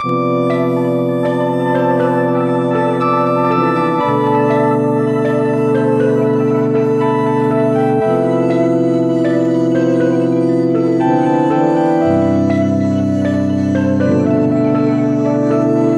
i like this loop i made on the evoke today. i wanted to make a sample to chop up on the p6 so this is what i rolled up. i like the different granular engine presets, i dont know how to really dial in a sound with the granular engine so its nice to have some examples of where it can go beyond just shimmer sounds.